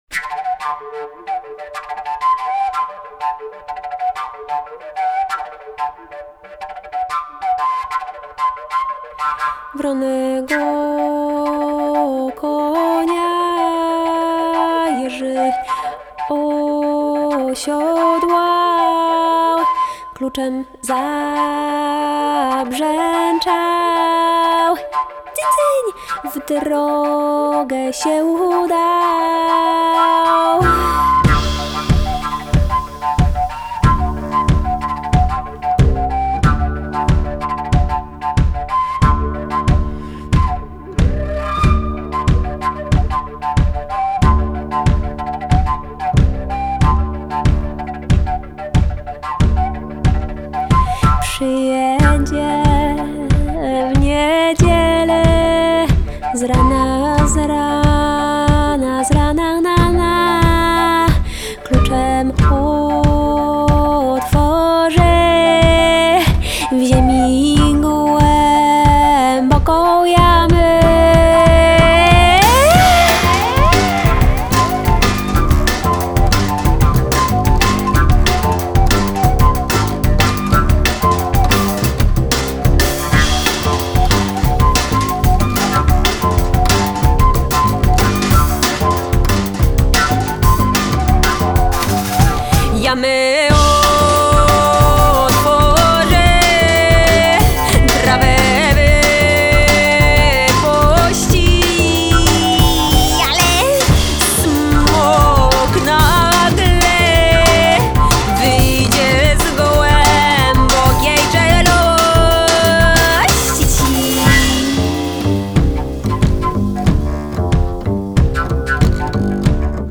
Genre: Vocal Jazz, Avant-Garde, Folk
klarnet, drumla, śpiew (clarinet, jaws harp, vocal)
kontrabas (double bass)